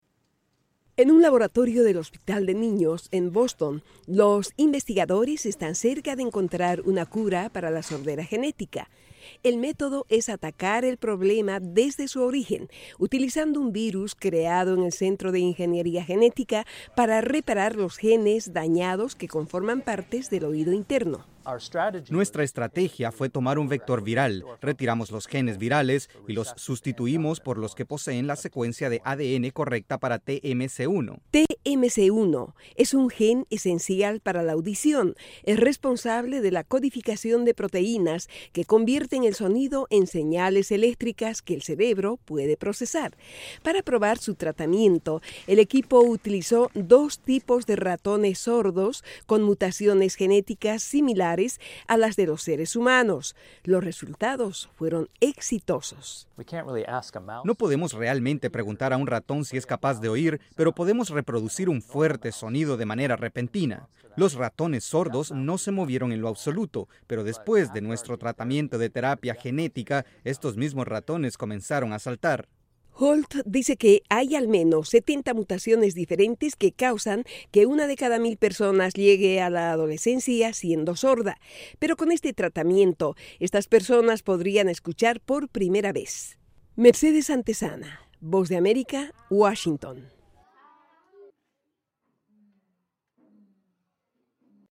Tratamiento para personas que nacieron sordas Descripción: Investigadores en Boston lograron restaurar con éxito la audición en ratones sordos, utilizado la que llaman "terapia genética". Desde los estudios de la Voz de América en Washington informa